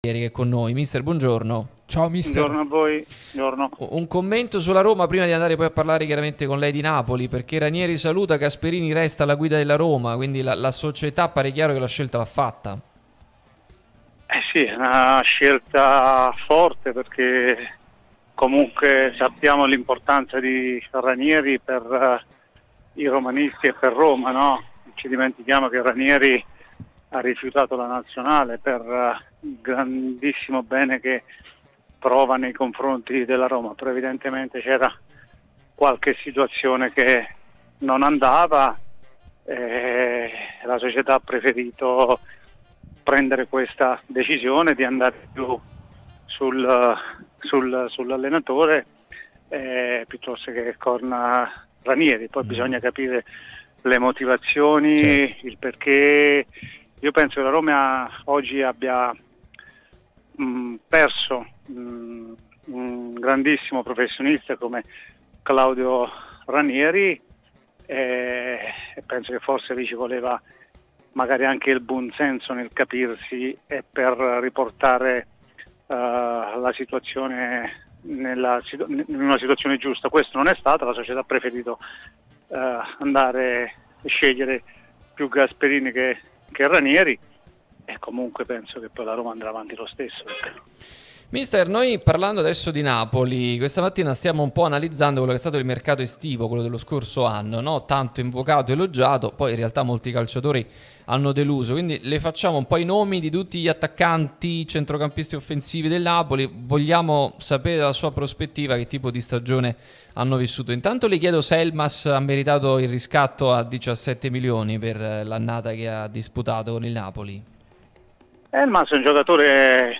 Carmine Gautieri, ex giocatore del Napoli, oggi allenatore, è intervenuto su Radio Tutto Napoli, l'unica radio tutta azzurra e live tutto il giorno, che puoi seguire sulle app gratuite (per Iphone o per Android, Android Tv ed LG), in DAB o qui sul sito anche in video.